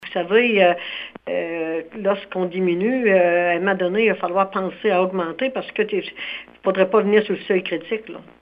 Délisca Ritchie Roussy affirme que sa population a connu une légère baisse dans les derniers mois. Si la tendance perdure, le conseil municipal n’aura possiblement pas le choix d’augmenter les taxes dans sa municipalité :